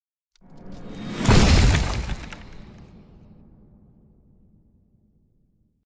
Звуки Impact
Слушайте онлайн и скачивайте бесплатно качественные ударные эффекты, которые идеально подойдут для монтажа видео, создания игр, рекламных роликов и постов в соцсетях.